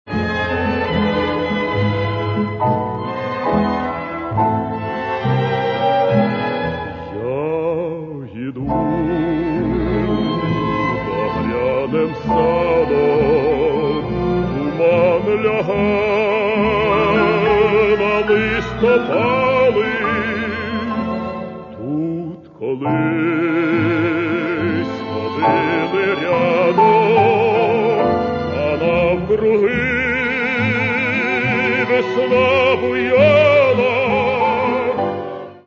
Каталог -> Эстрада -> Певцы